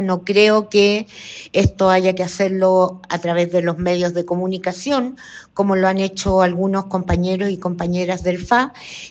Tanto así que la diputada Lorena Fries planteó que es relevante que el análisis político de la elección se realice en las instancias colectivas que correspondan.